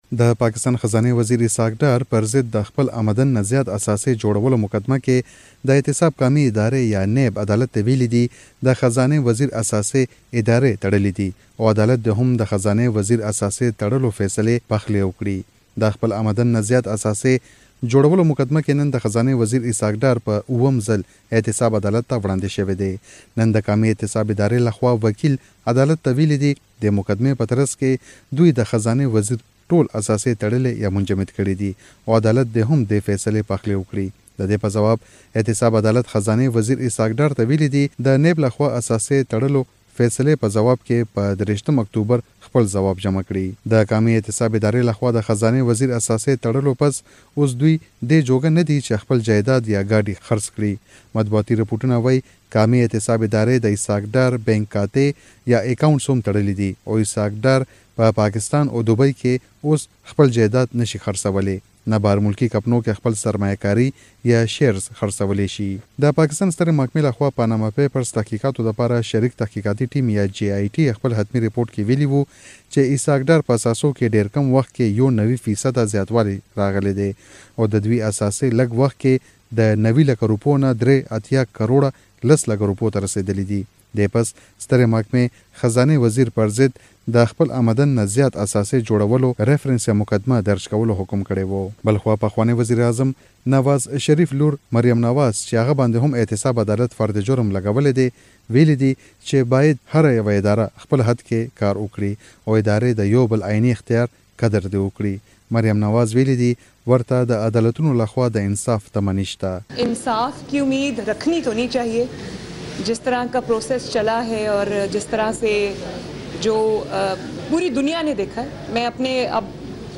رپورټ